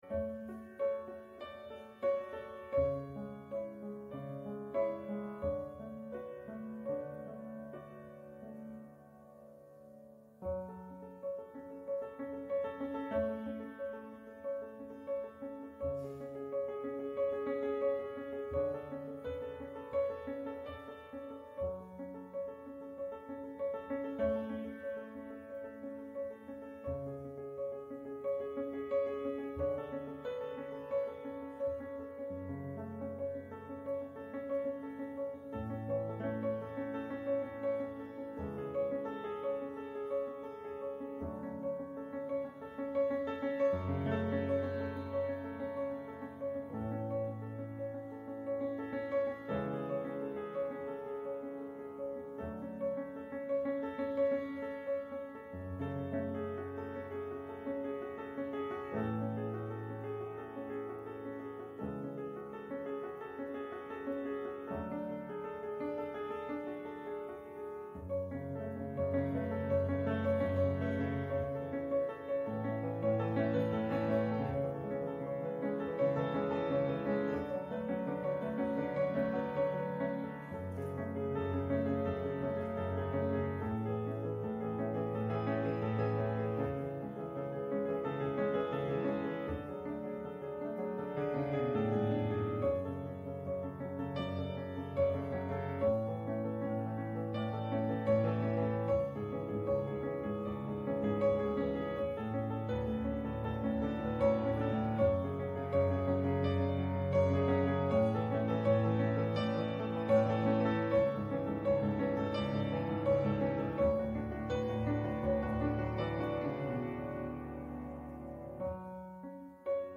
Radiogottesdienst am 12. Oktober 2025 aus der Christuskirche Altona